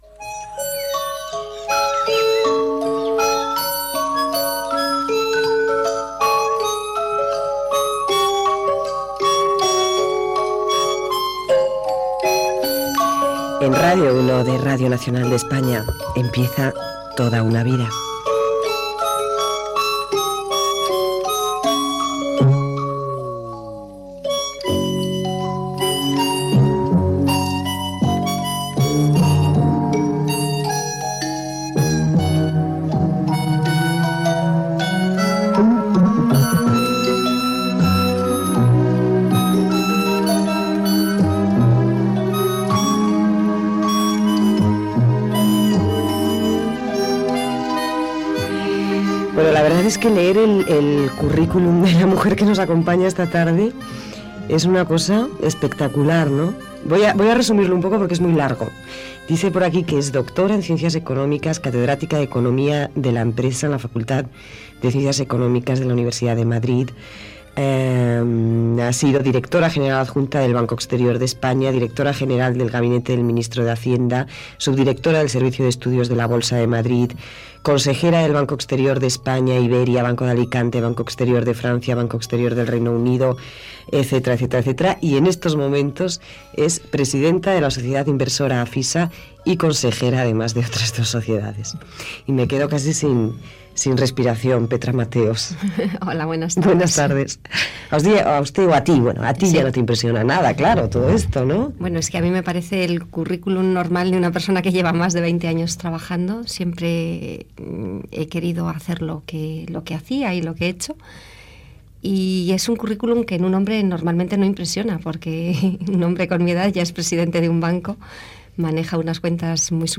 Sintonia, presentació de la invitada i entrevista